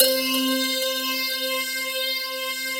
FLNGHARPC4-L.wav